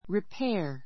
ripéə r リ ペ ア